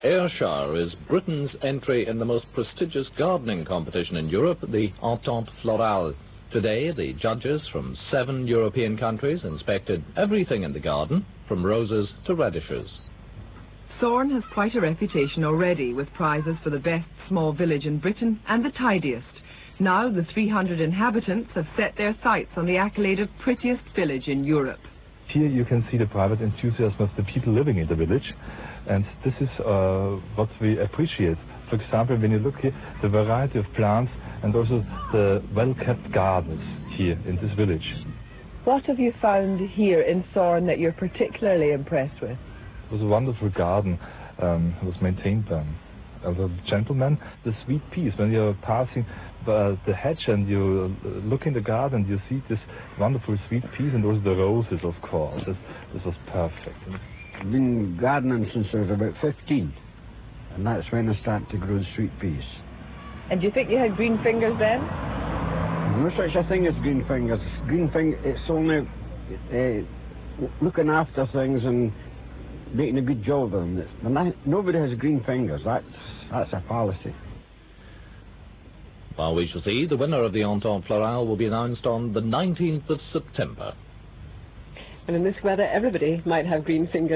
Interview
on television news about his sweet peas